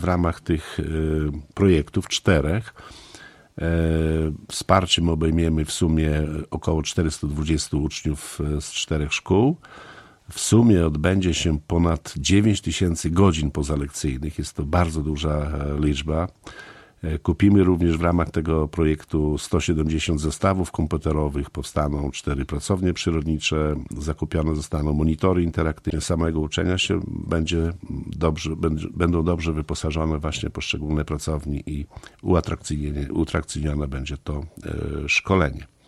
Szczegóły przedstawił w środę (20.06) gość Radia 5 Wacław Olszewski, burmistrz Olecka.